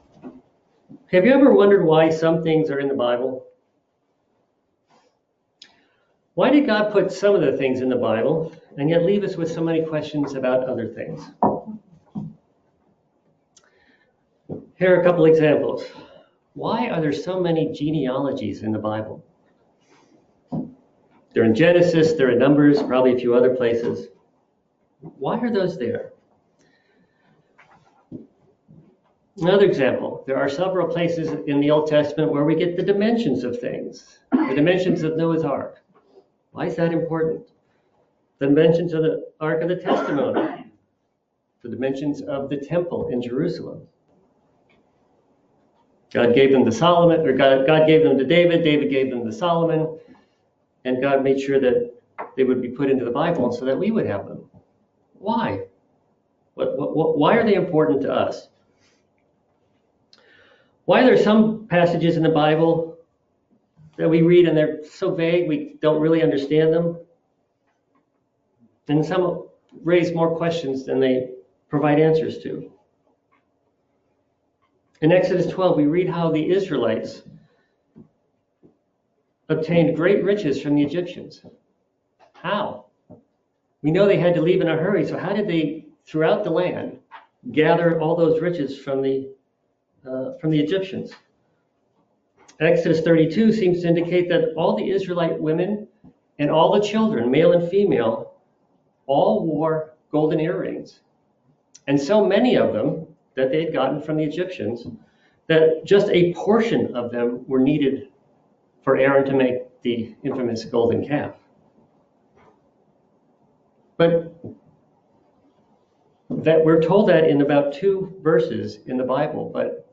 Given in Philadelphia, PA